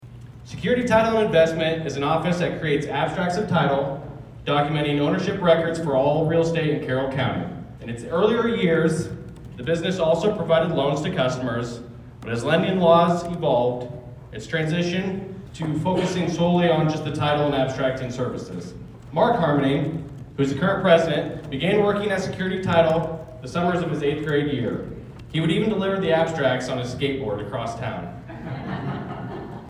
The Carroll Chamber of Commerce hosted its annual banquet Thursday evening and presented awards to community leaders, volunteers, and local businesses.